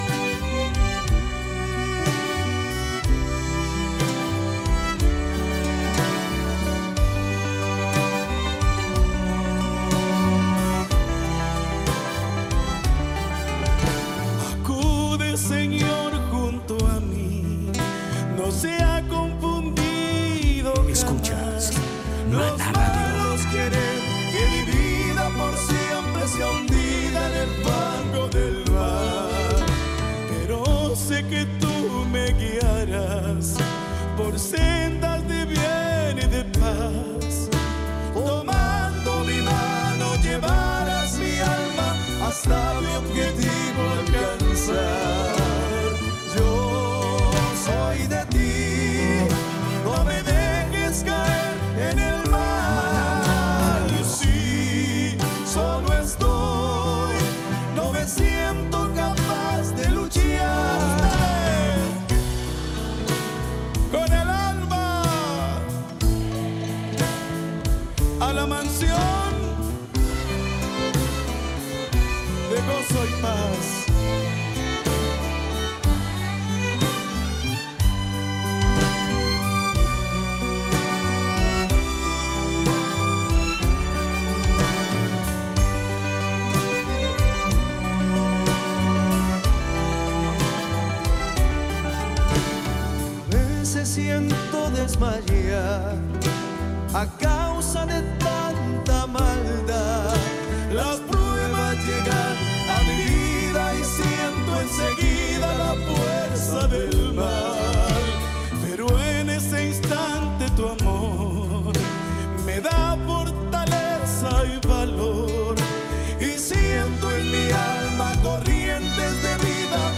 Identificació de l'emissora, cançó i comentari religiós sobre la creació de l'ésser humà
Religió
FM